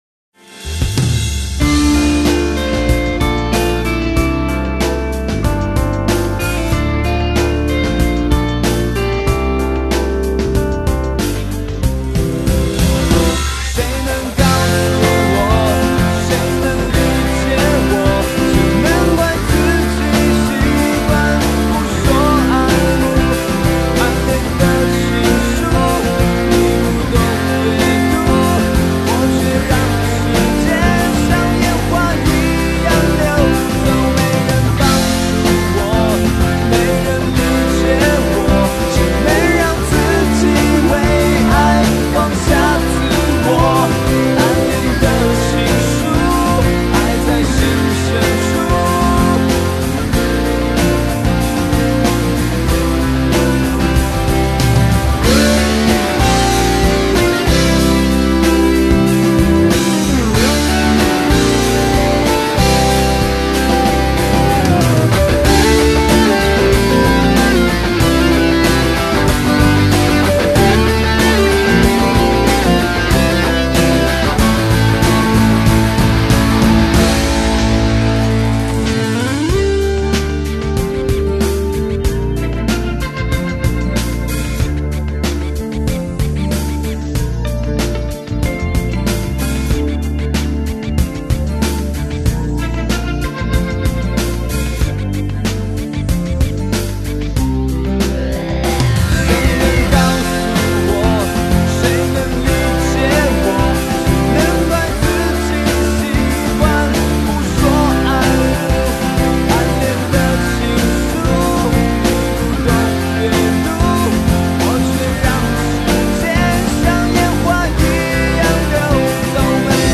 伴奏下载